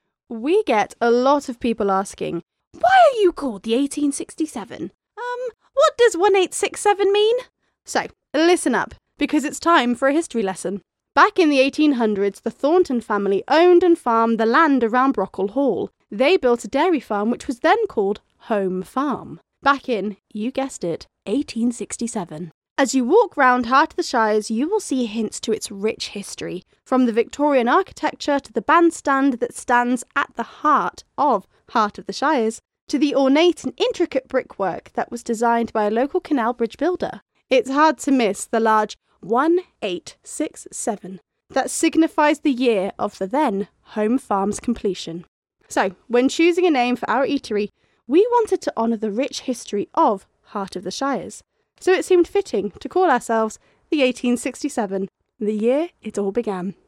History-of-the-1867-VO.mp3